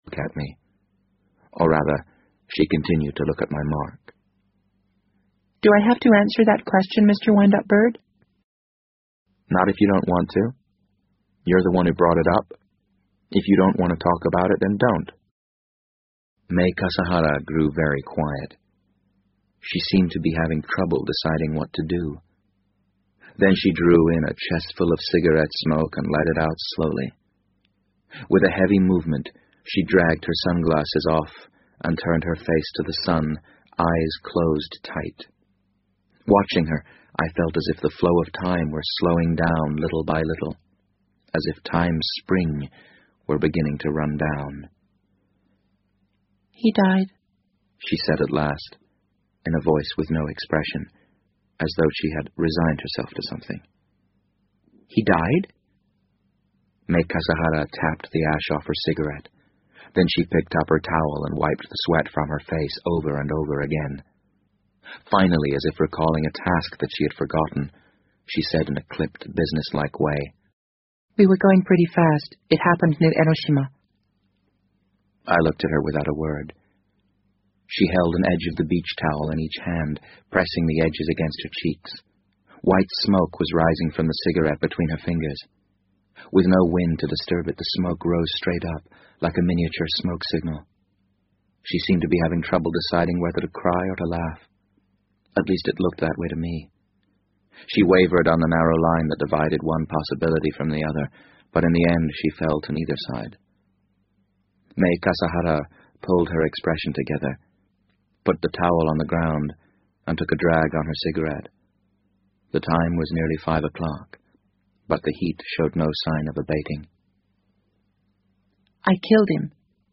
BBC英文广播剧在线听 The Wind Up Bird 008 - 19 听力文件下载—在线英语听力室